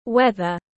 Thời tiết tiếng anh gọi là weather, phiên âm tiếng anh đọc là /ˈweð.ər/.
Weather /ˈweð.ər/
Weather.mp3